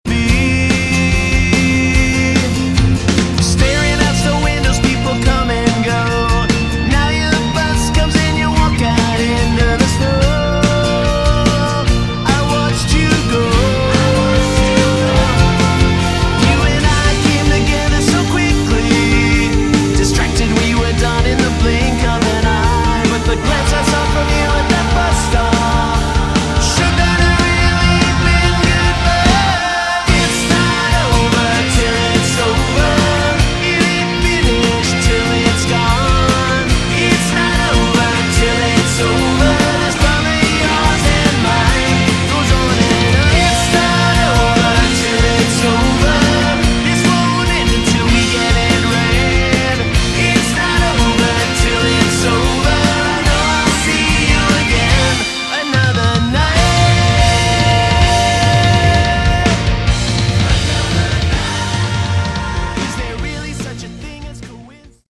Category: Melodic Hard Rock
vocals
bass
guitars
keyboards
drums